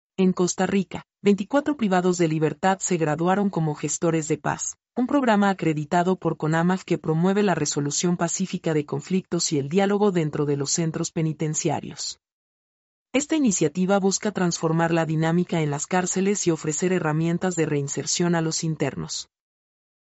mp3-output-ttsfreedotcom-100-1.mp3